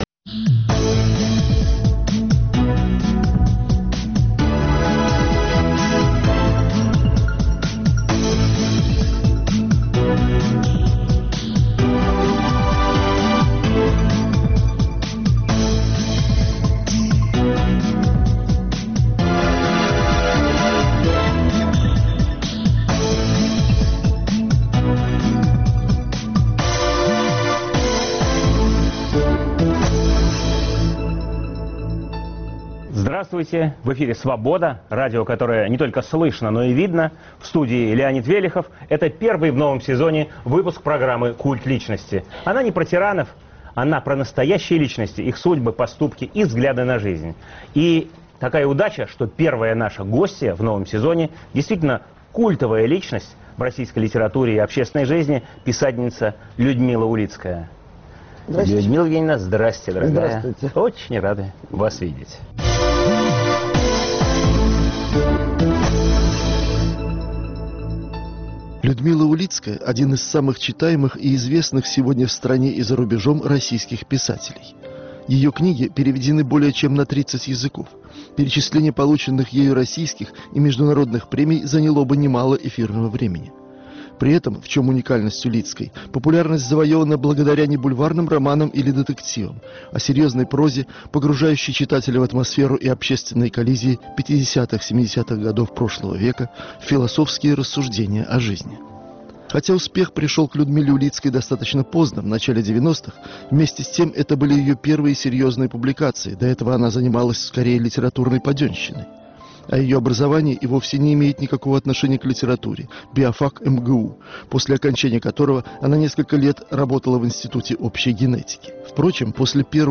В студии "Культа личности" писатель Людмила Улицкая.